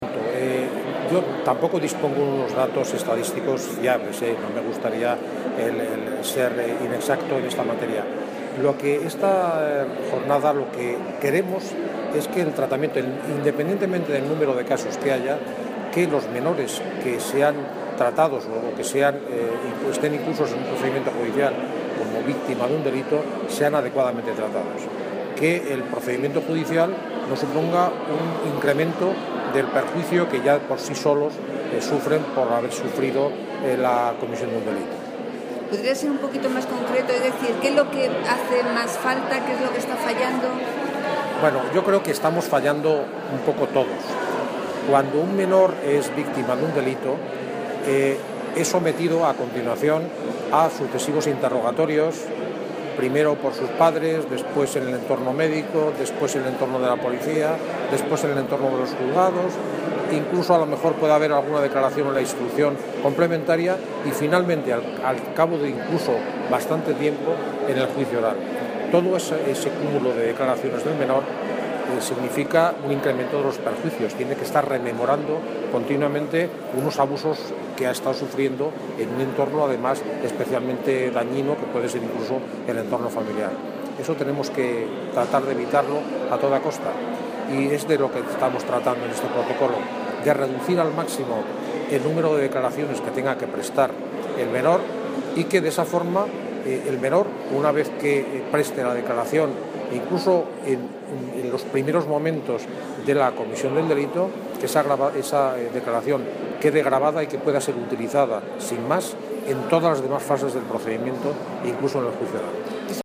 Audio - Francisco Javier Vieira (Presidente del Tribunal Superior de Justicia de Madrid) Sobre jornadas malos tratos